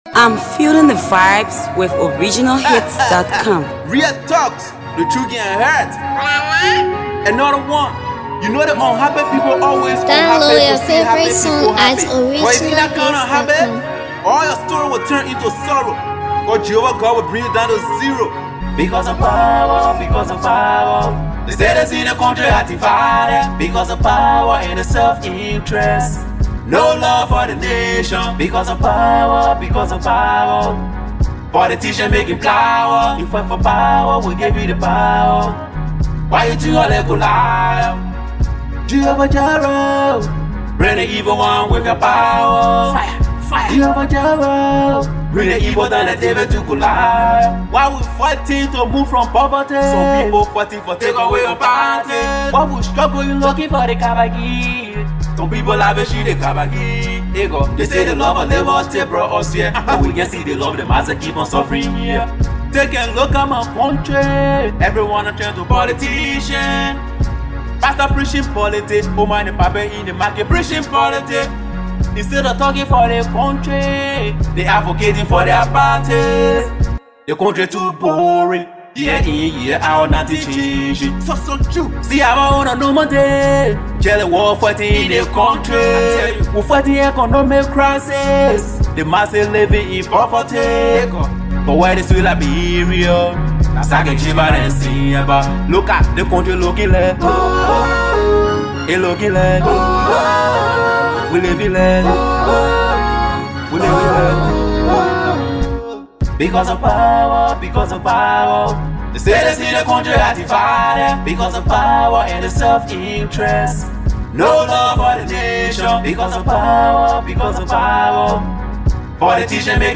heartfelt jam
This jam is emotional and exceptional.